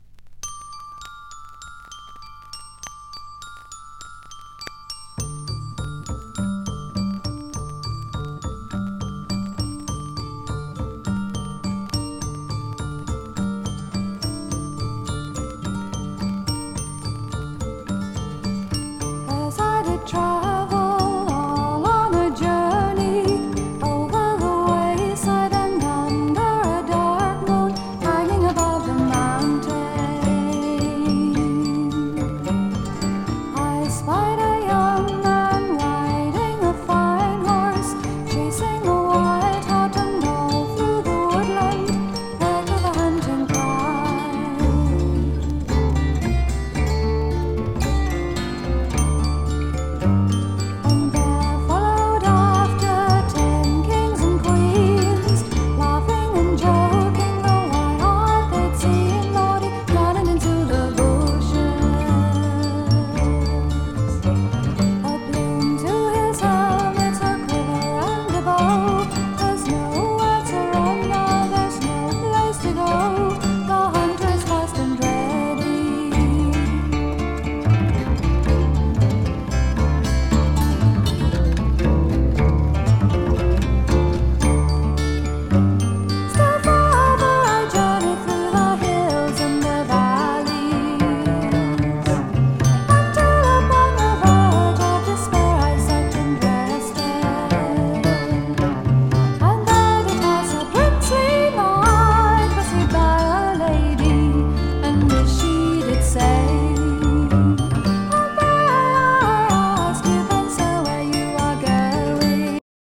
ベースとドラムがジャズみたいな働きっぷりでそこにかっこいいギターが乗っかり、天使のような歌声が。。。
盤質：B+（静音部などチリつくとこあり） ジャケット：縁・角スレ、両面・内ジャケ汚れ。